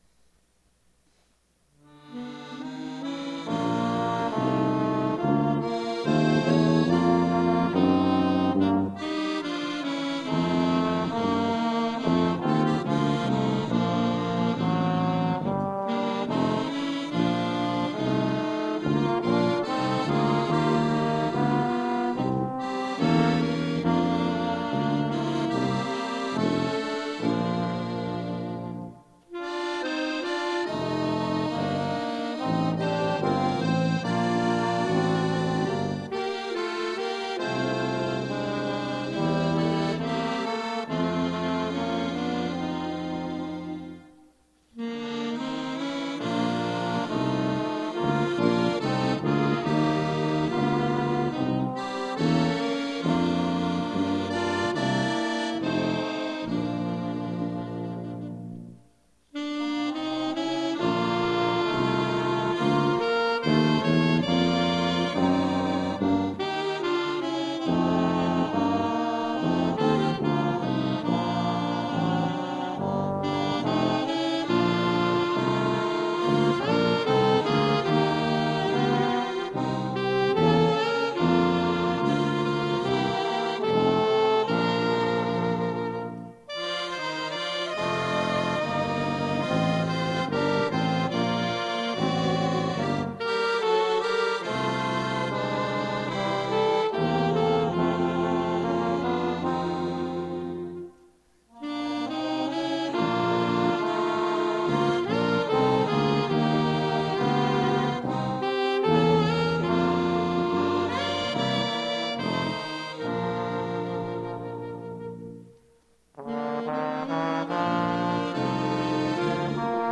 acordeon
sax-alto
trombone
tuba